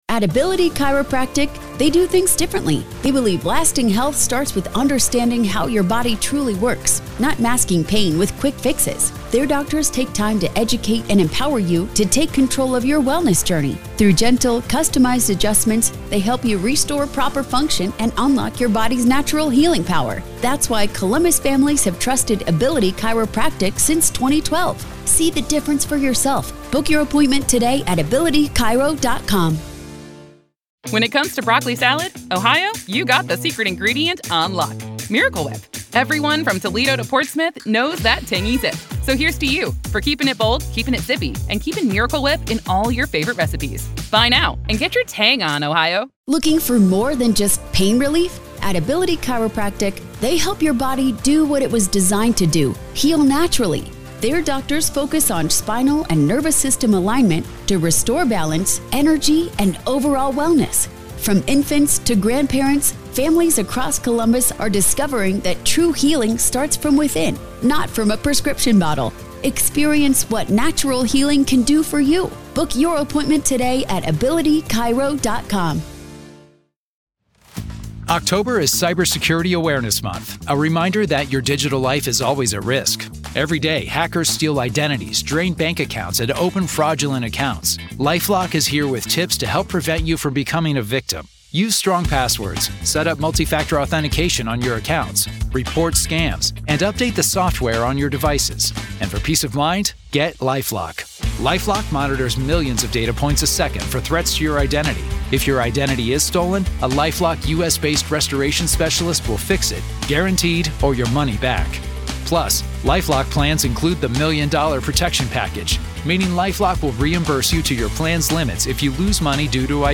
MA v. Karen Read Murder Retrial - Motions Hearing Day 2 PART 2